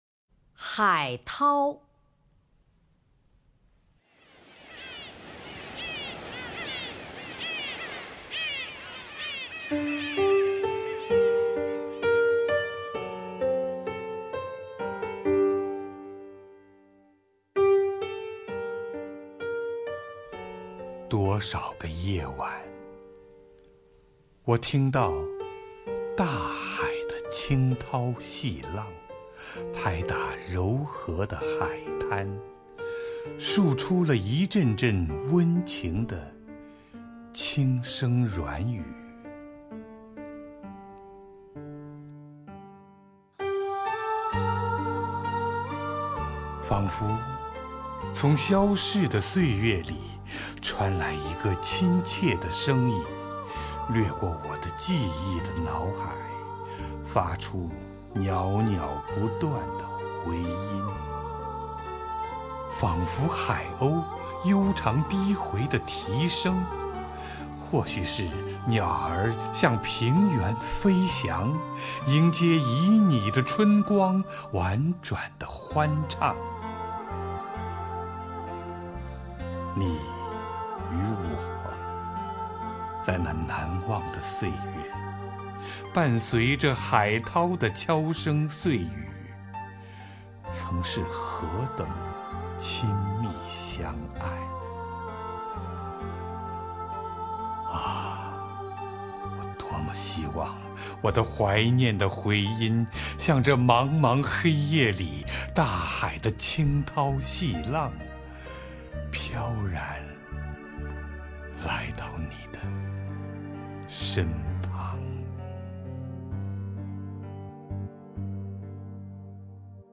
首页 视听 名家朗诵欣赏 乔榛
乔榛朗诵：《海涛》(（意）萨瓦多尔·夸西莫多)